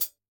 normal-slidertick.ogg